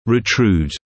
[rɪ’truːd][ри’труːд]перемещать кзади, проводить ретрузию